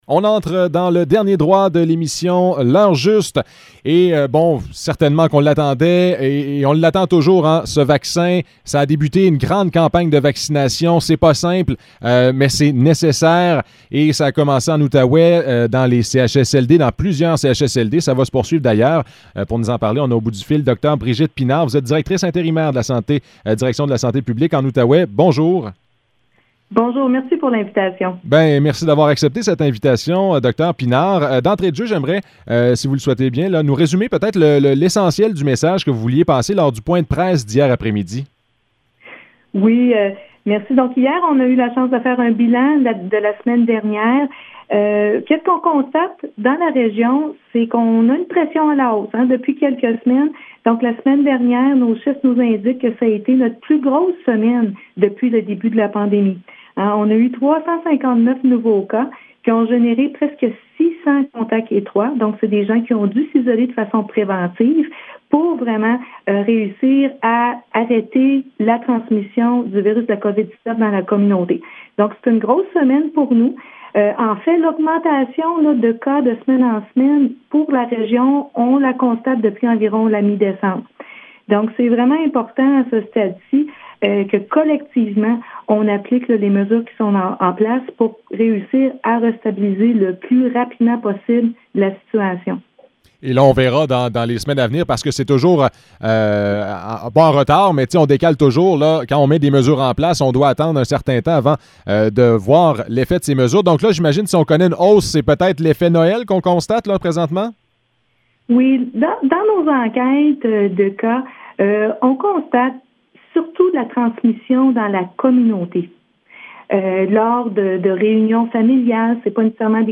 Entrevue avec Dre Brigitte Pinard